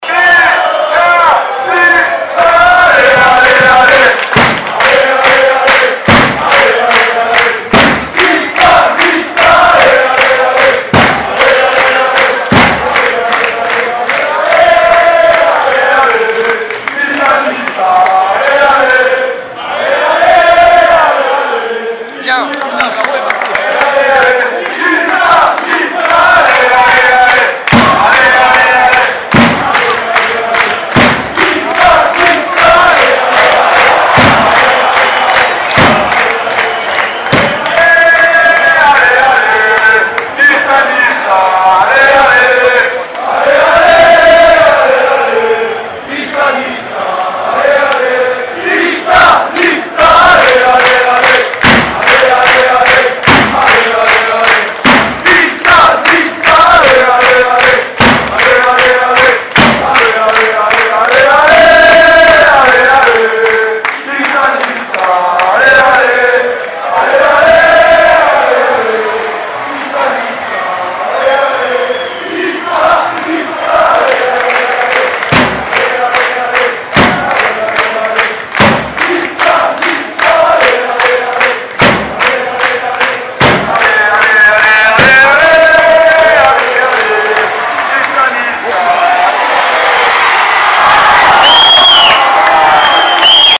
Chant de supporters